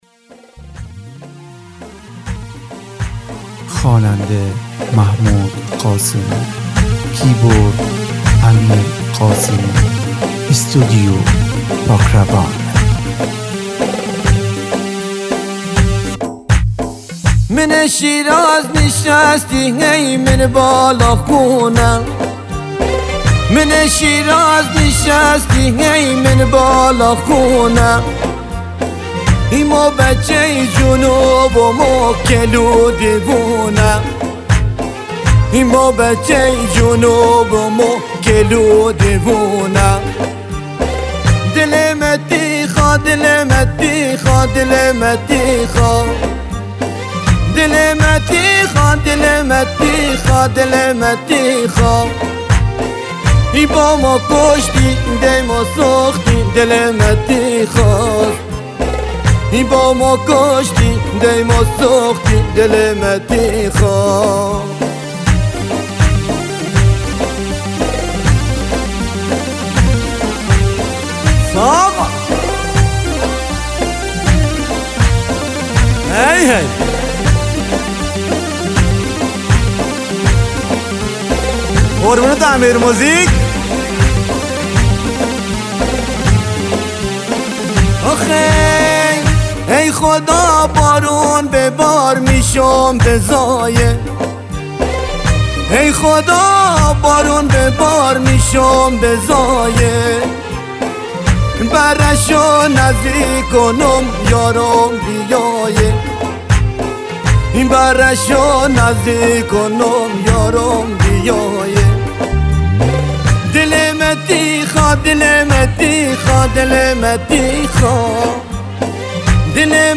کیبورد